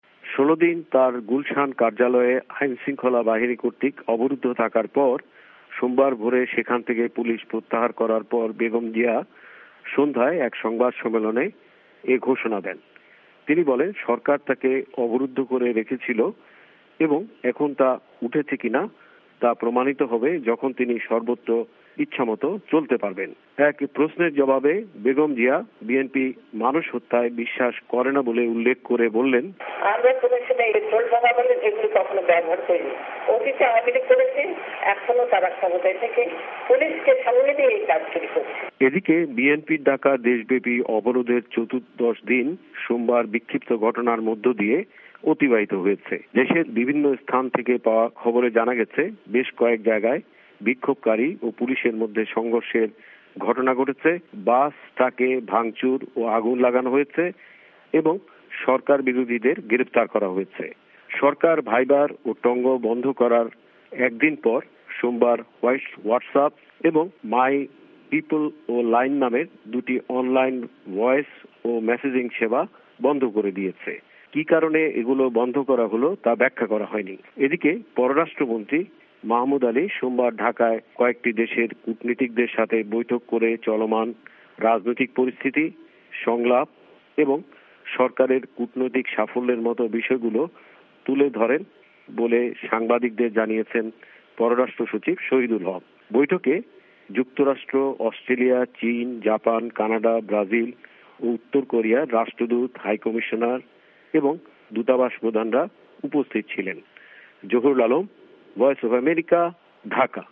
ভয়েস অফ এ্যামেরিকার বাংলাদেশ সংবাদদাতাদের রিপোর্ট